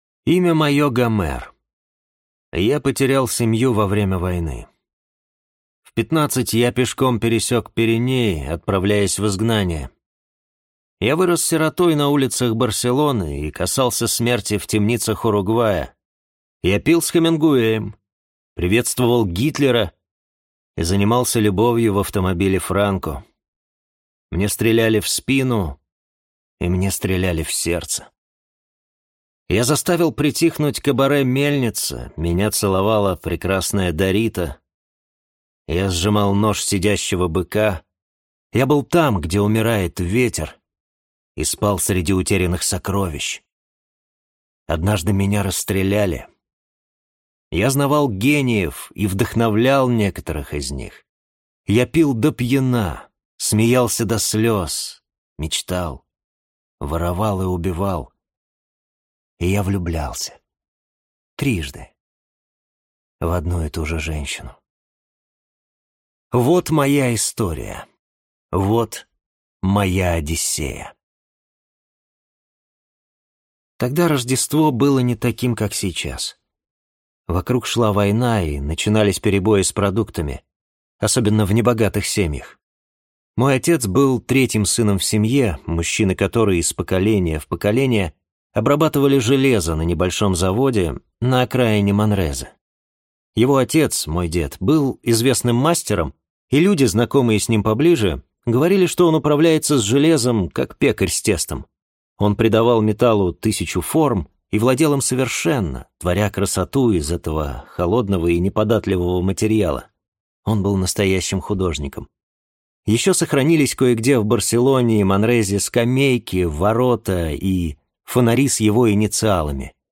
Аудиокнига Память – это ты | Библиотека аудиокниг